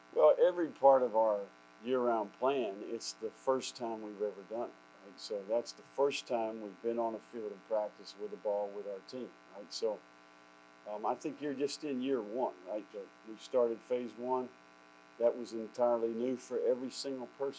In his first press conference since spring practice started, Napier explained the style of practice he intends on running.